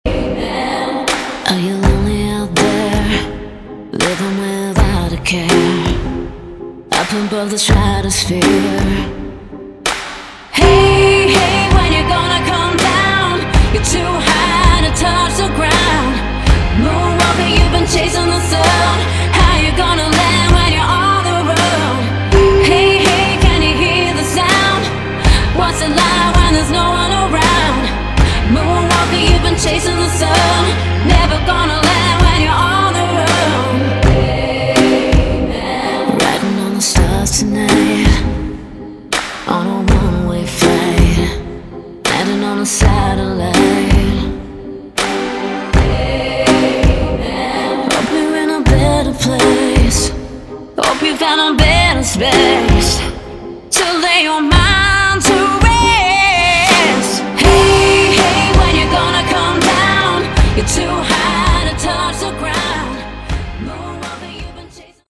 Category: Hard Rock
guitars, vocals, programming
drums, bass